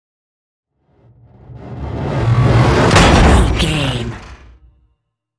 Electronic whoosh to metal hit
Sound Effects
dark
futuristic
intense
woosh to hit